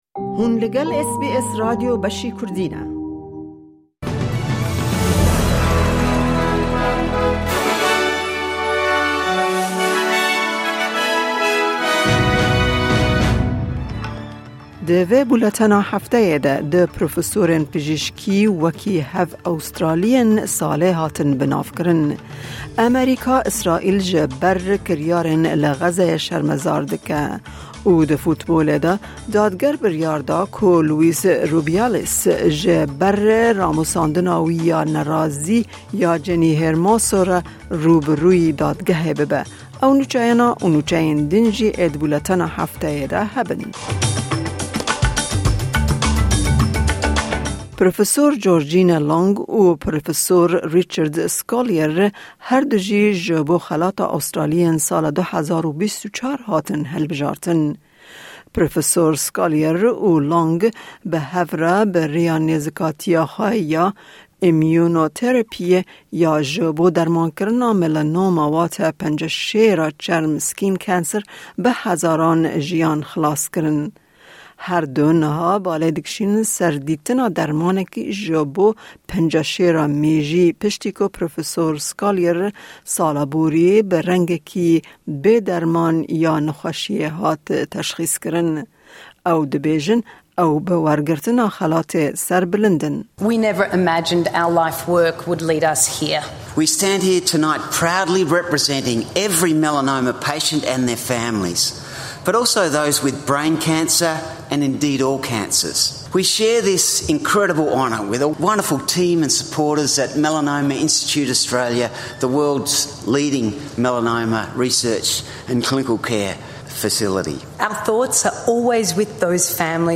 Nûçeyên Hefteyê